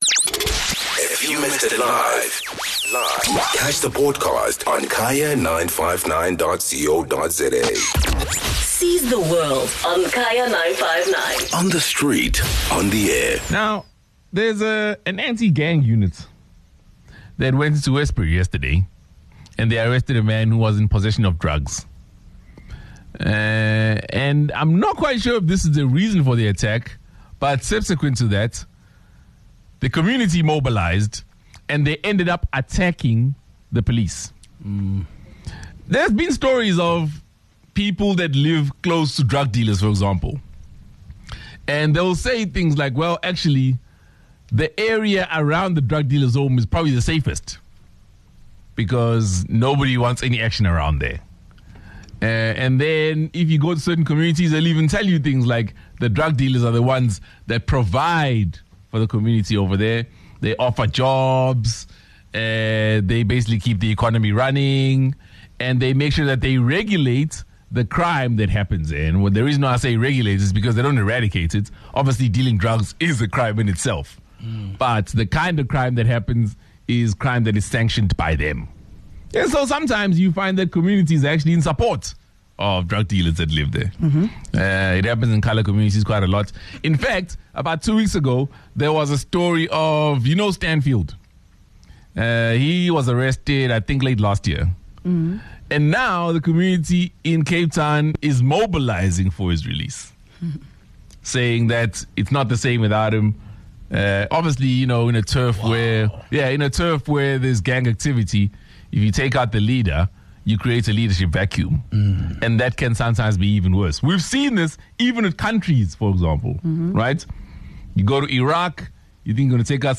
The Siz The World team discuss the events and on what to make of the attack on Police Anti-Gang Unit by the Westbury community yesterday after they had arrested a drug dealer.